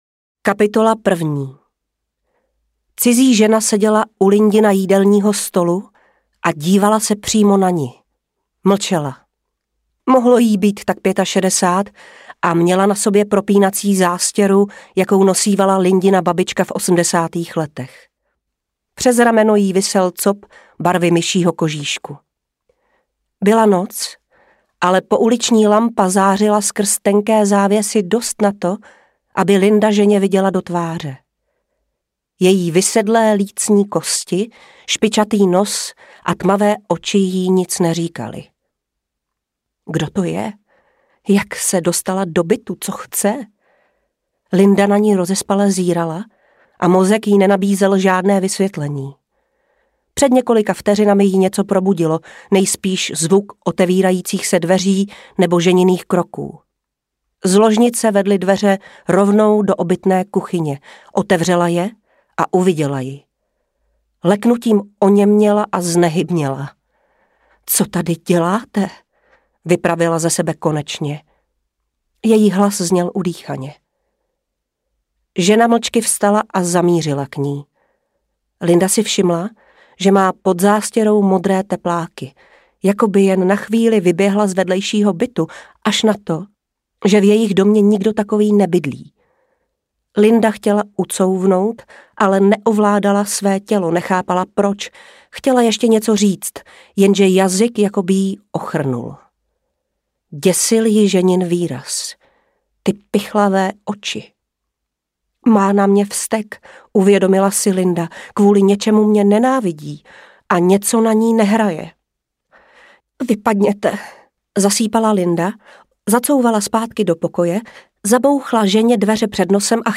Audiokniha
Čte: Petra Špalková